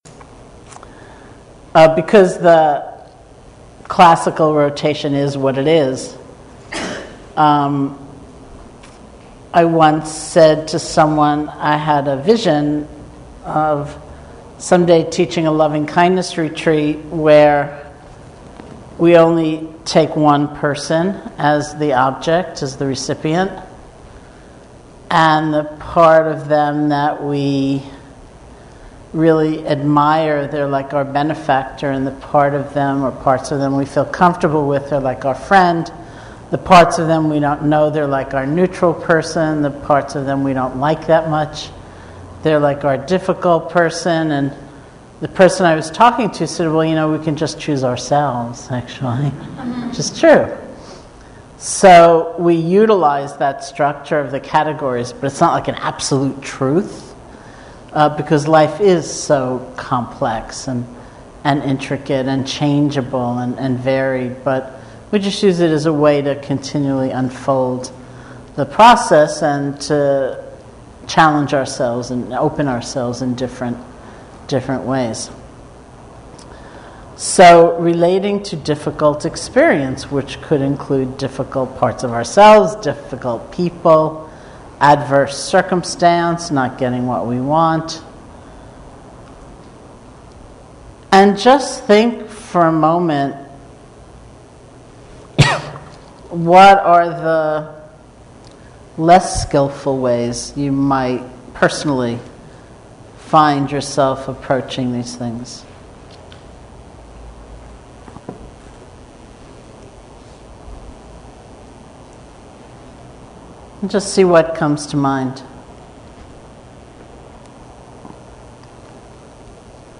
Teacher: Sharon Salzberg Date: 2014-07-19 Venue: Seattle Insight Meditation Center Series [display-posts] TalkID=237